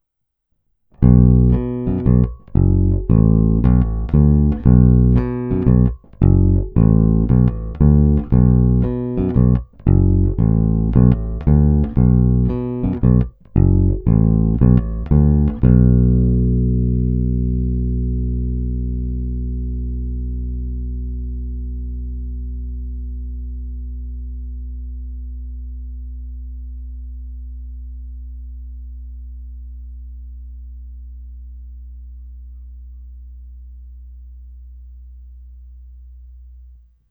Hra mezi snímačem a kobylkou
Parádní tučný, pevný, zvonivý zvuk s těmi správnými středy, které tmelí kapelní zvuk a zároveň dávají base vyniknout.